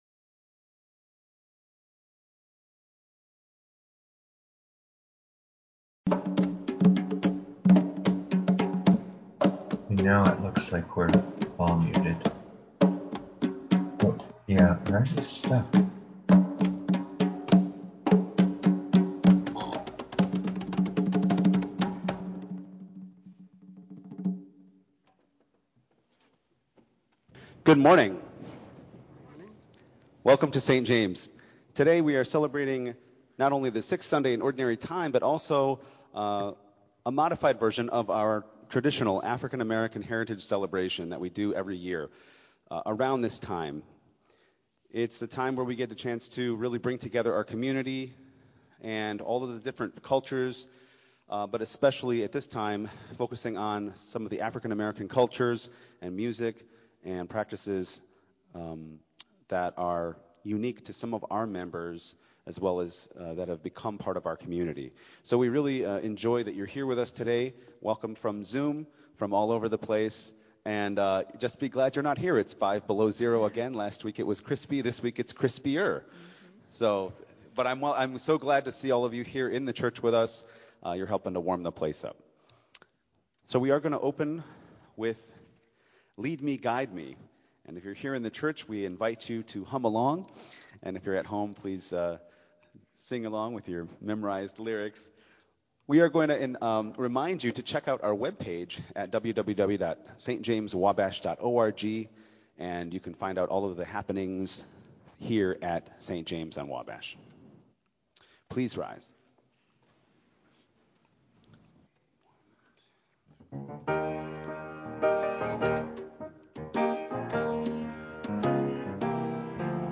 Sixth Sunday in Ordinary Time/African American Heritage Celebration